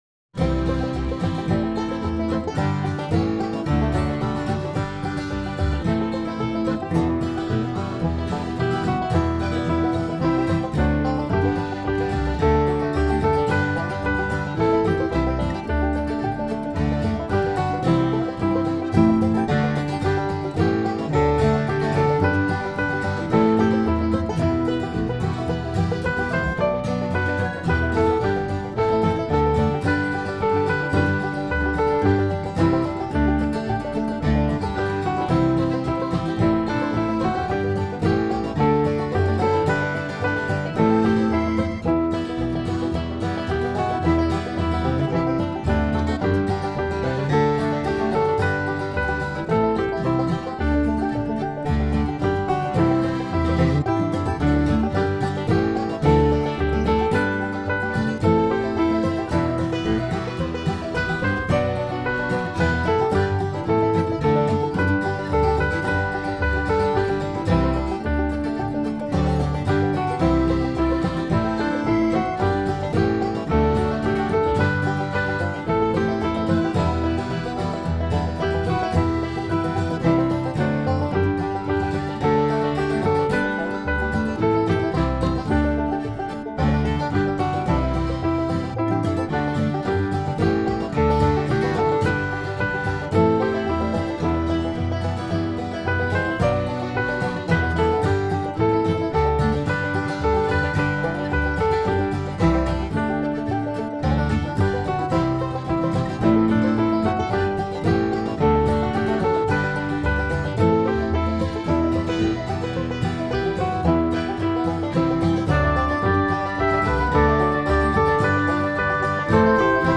descant line in the fourth verse